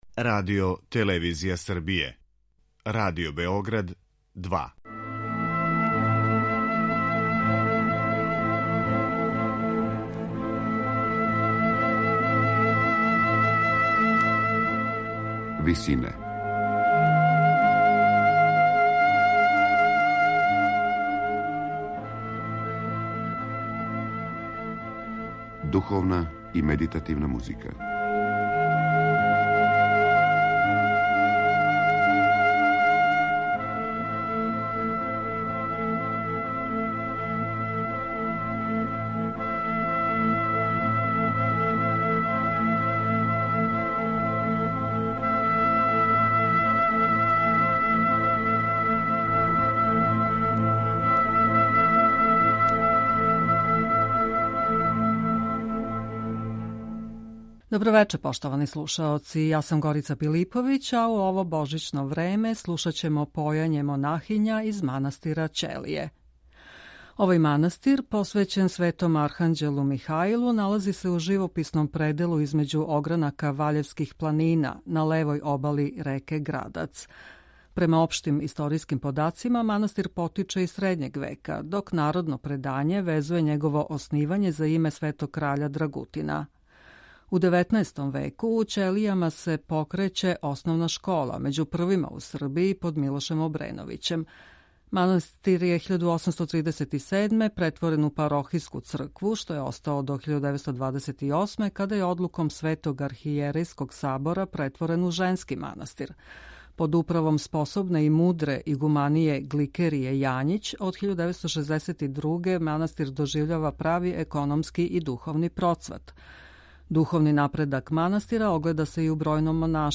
Појање монахиња из манастира Ћелије
Монахиње овог манастира, из чије је обитељи поникло још десет женских манастира и због чега је назван расадником монаштва, негују јединствено народно духовно појање. Вечерас ћемо прво чути две песме везане за божићне празнике, а затим низ песама посвећених различитим религиозним темама.
Оне су део традиционалне духовне песмарице раширене у народу, а њихова једноставна лепота, као и специфична боја гласова монахиња, освајају слушаоца непатвореним изразом.